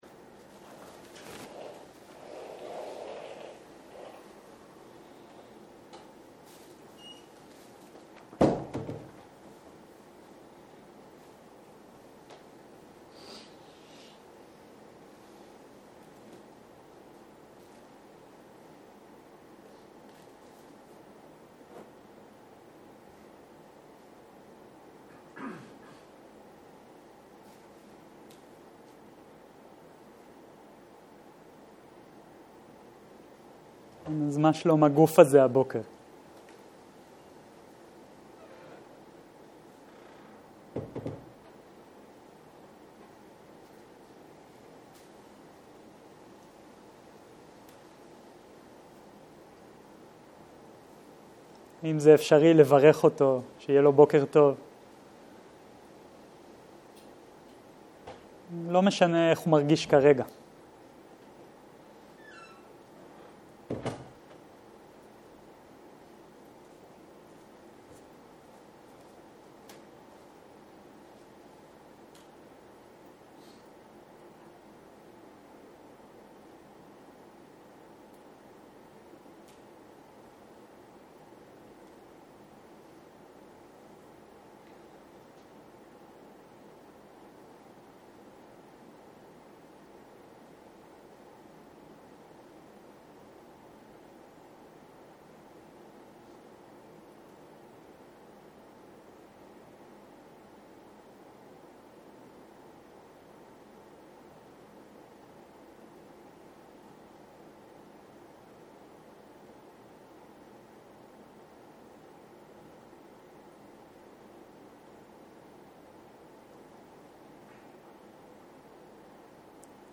11.02.2023 - יום 4 - בוקר - הנחיות מדיטציה - מיומנות עם חמשת המכשולים - הקלטה 5